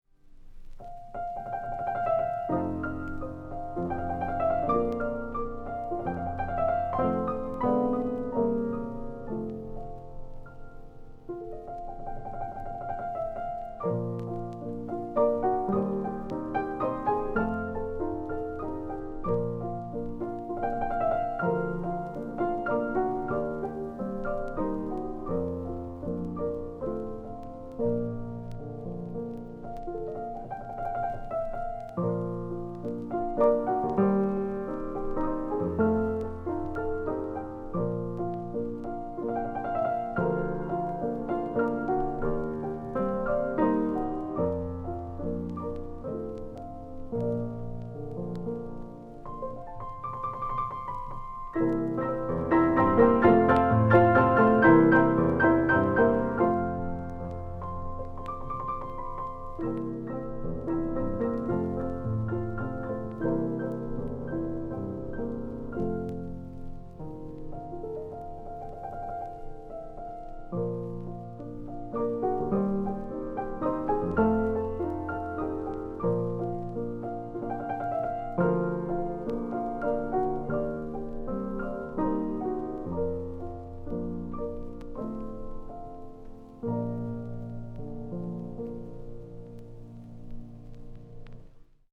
Sarau de Sinhá – Lundu